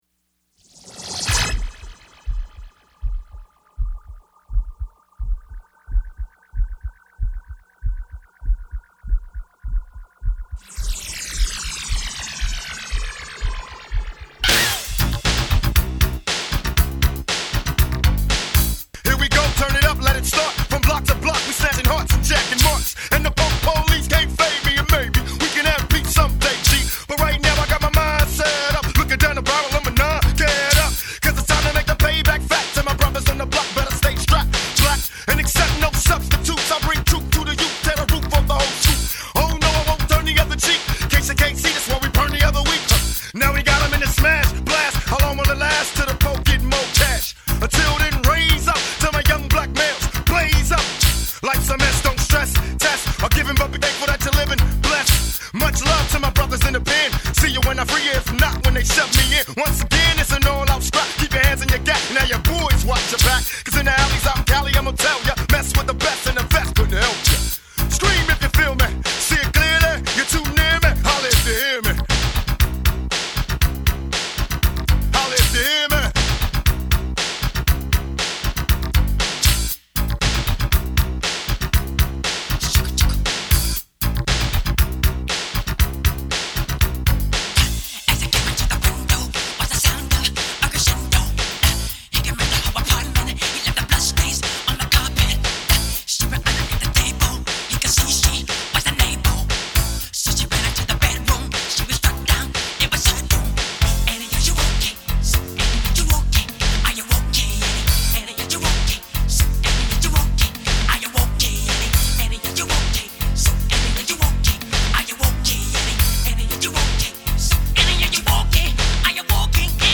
This mix features 2 of the best that ever did it.